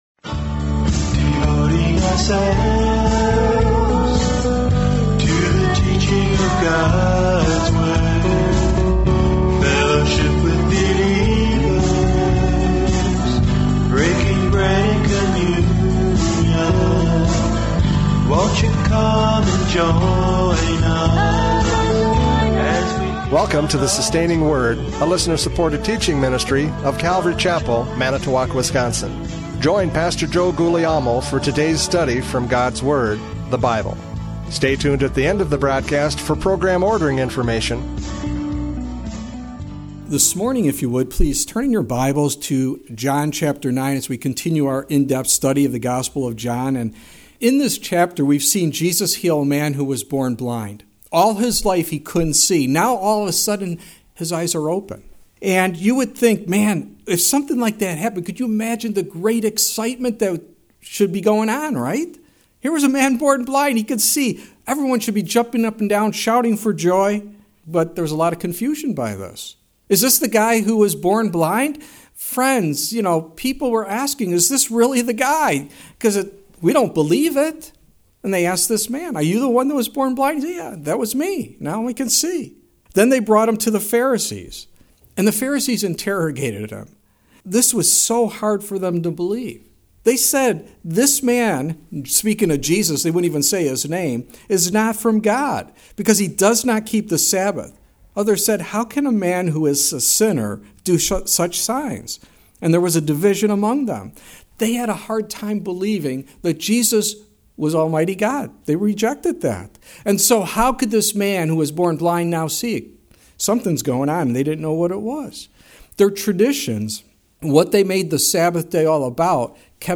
John 9:35-41 Service Type: Radio Programs « John 9:8-34 The Inquest!